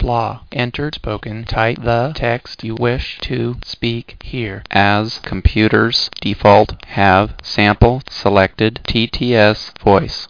As for what the voice data for the sample TTS engine sounds like, here is the VCE file played as raw PCM.